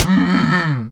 minecraft / sounds / mob / camel / death2.ogg